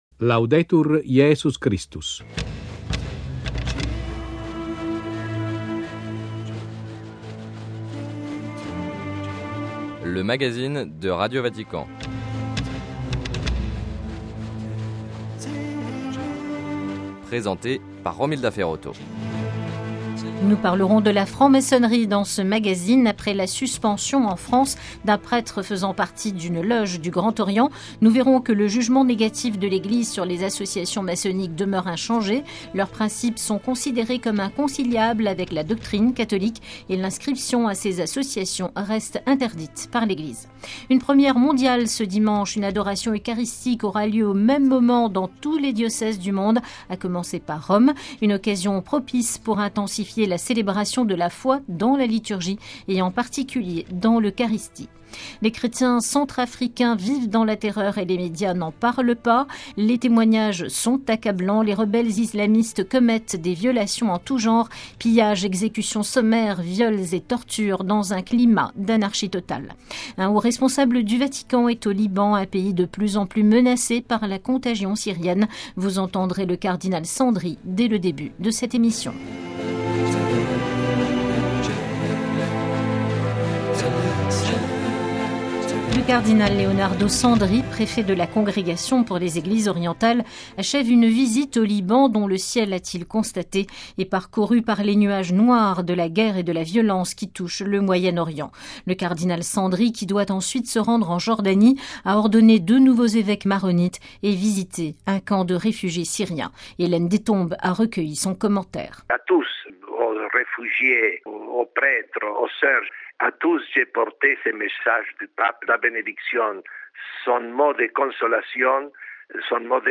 Sommaire : - Entretien avec le cardinal Leonardo Sandri, préfet de la Congrégation pour les Eglises orientales, qui effectue une visite au Liban.